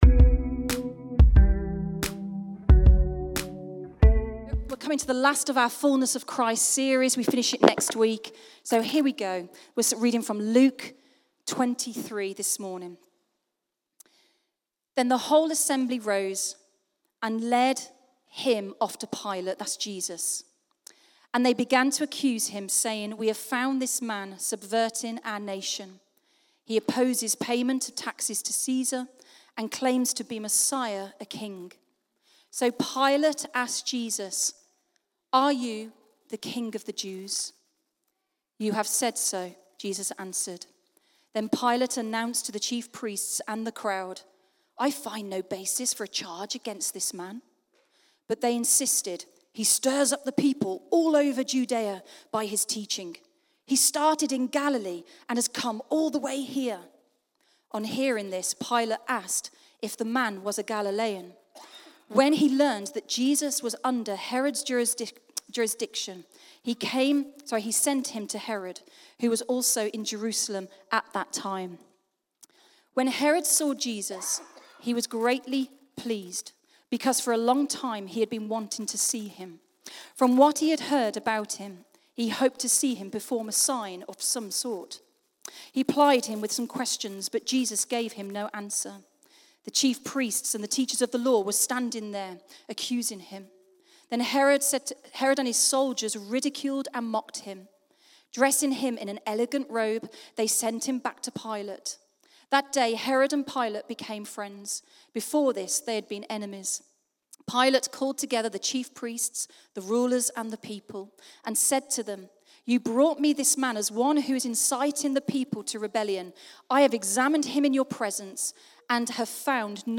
Sunday Messages | The Fullness of Christ